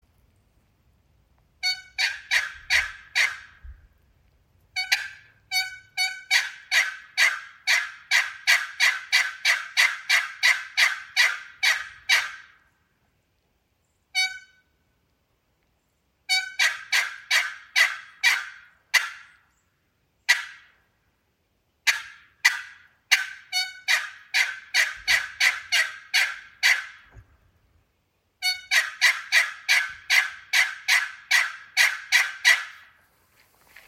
December-27-Bloodwood-and-Curly-Maple-Youngblood.mp3